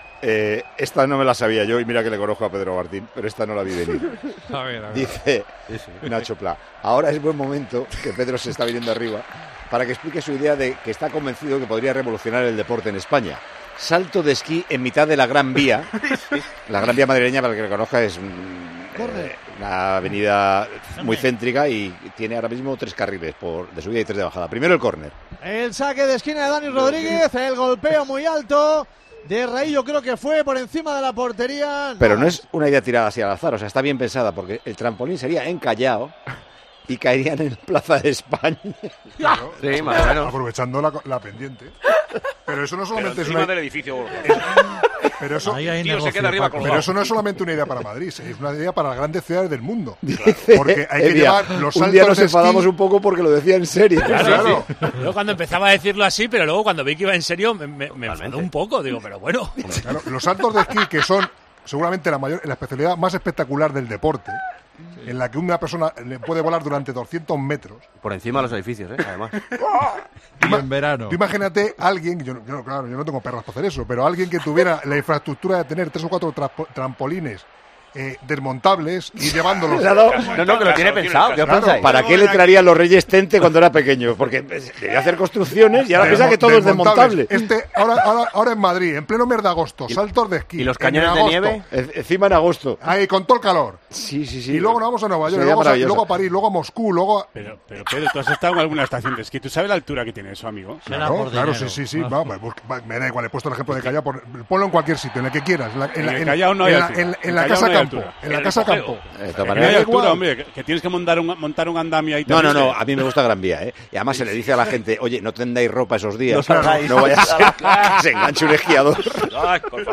Idea que provocó la carcajada de todo el equipo.